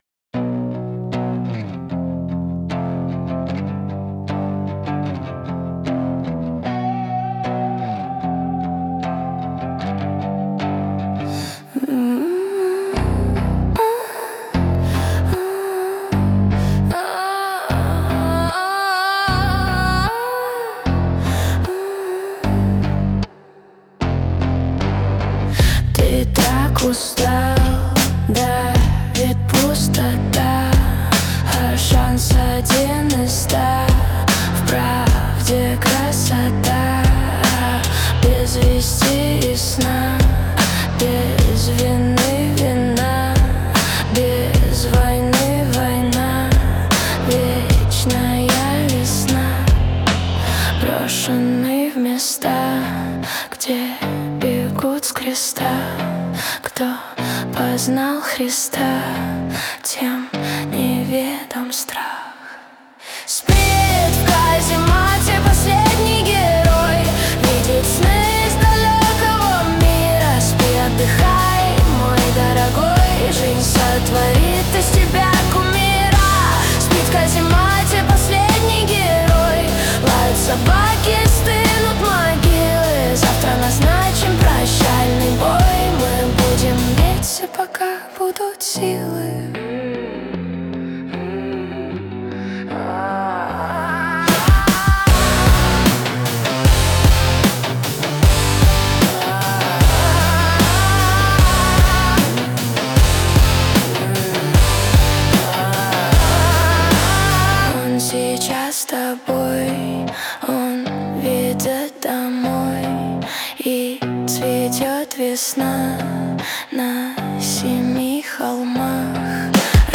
Raw gritty trip-hop grunge hybrid. Verses: soft intimate hushed female vocal over lo-fi trip-hop drums, dirty bass, and muted grunge guitars. As the song rises, the vocal grows rougher. Choruses hit with loud distorted guitars and intense female vocals with rasp, grit, and emotional strain.
Во-первых, общий характер — сырой трип-хоп с гранжевой энергией.
В новом варианте песня стала более резкой и динамичной: усилился контраст между частями, вокал стал жёстче, а финал получил ярко выраженный эмоциональный пик.